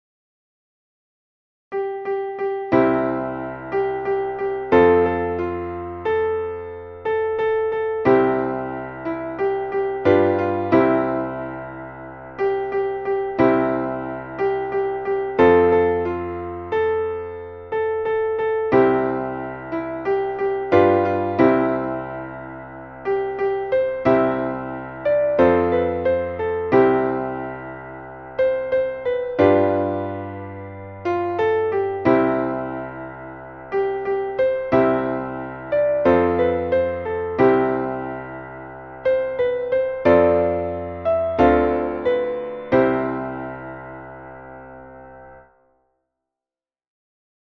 Traditional / Spiritual / Gospel